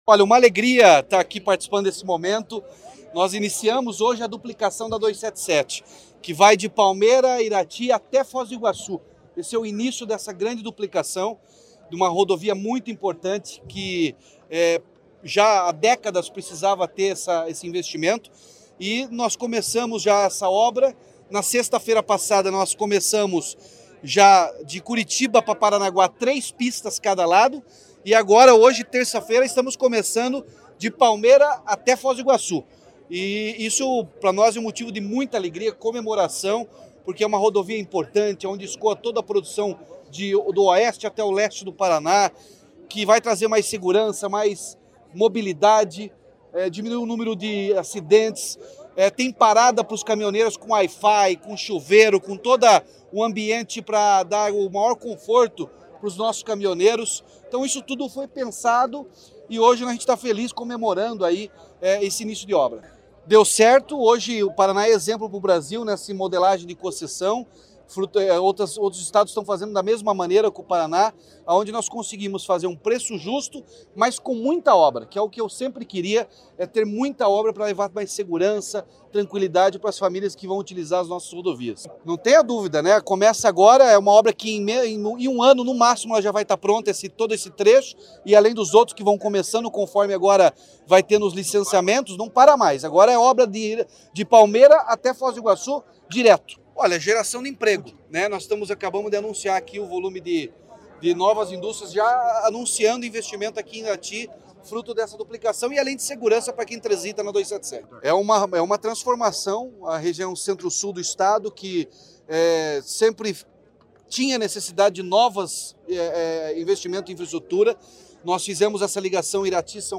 Sonora do governador Ratinho Junior sobre o início da duplicação da BR-277 em Palmeira e Irati